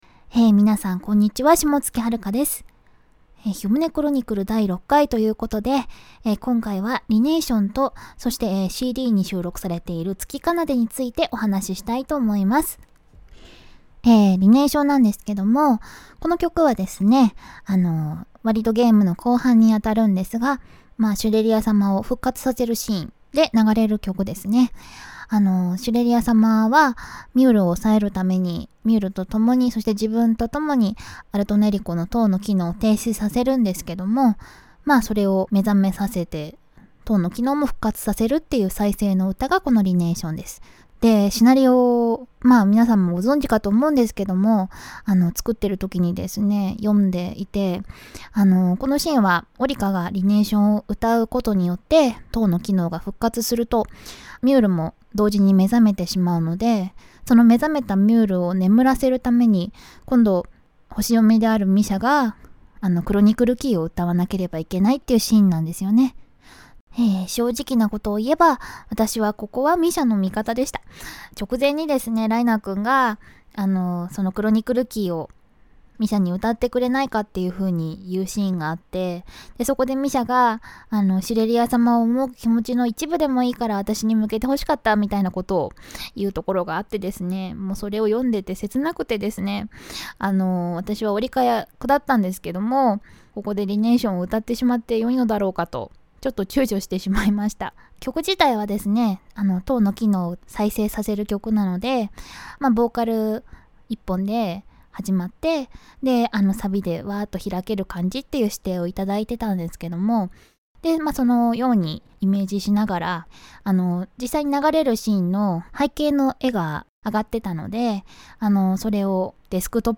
歌い手さんには、霜月はるかさんにインタビューをさせていただき ました。
▼霜月さんからのメッセージ